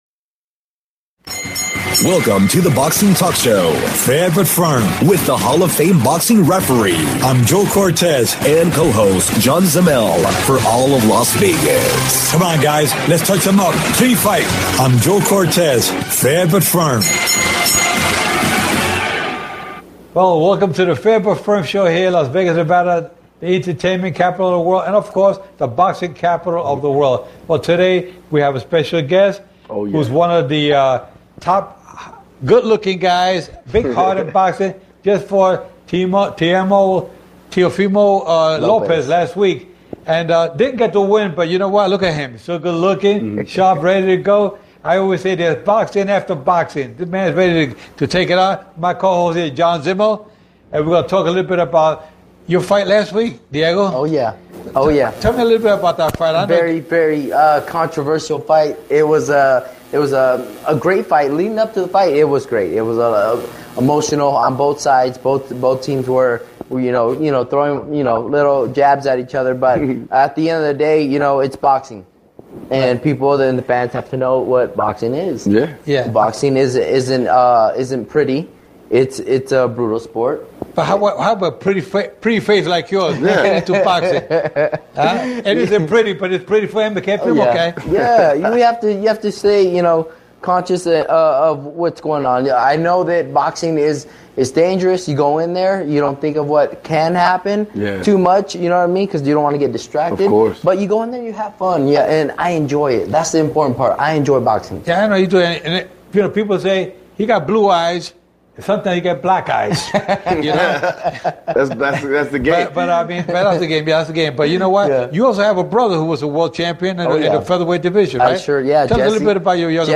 SPECIAL GUEST former NABF Super Featherweight Champion "Mr. Superb" Diego Magdaleno